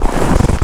STEPS Snow, Walk 29-dithered.wav